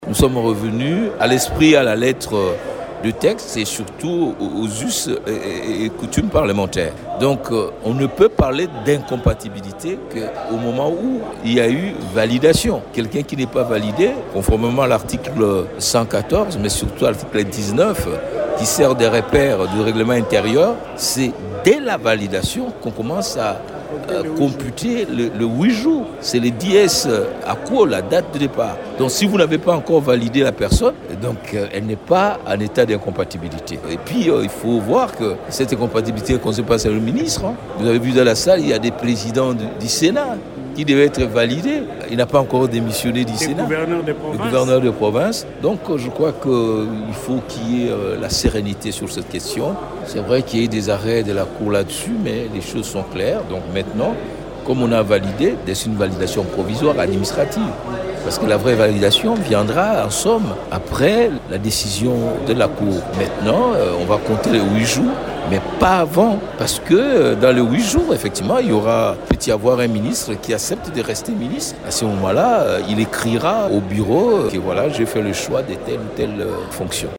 Le malentendu a été dissipé, conformément aux us et coutumes de la chambre basse, explique Jacque Djoli.